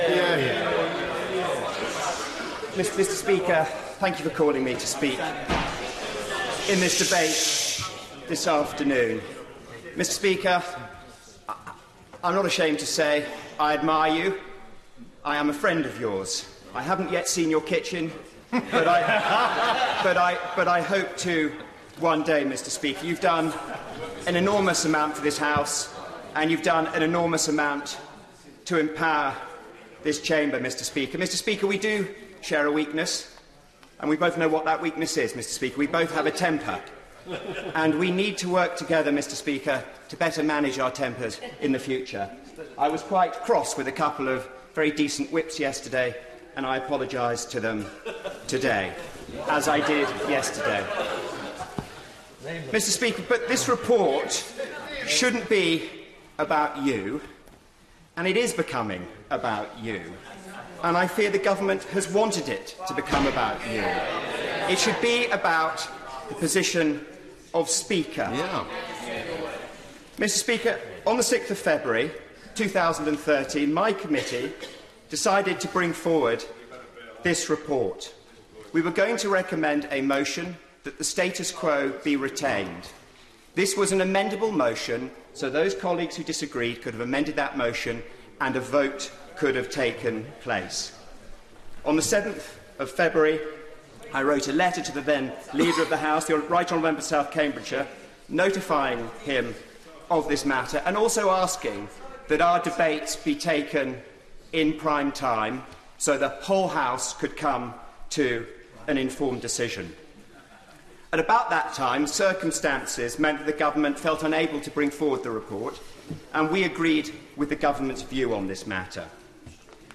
Charles Walker gives emotional speech on much-criticised secret Speaker ballot plot